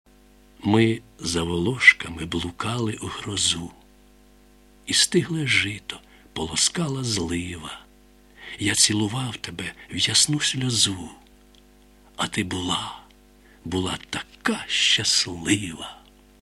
Справді, давній вірш, озвучений сьогодні...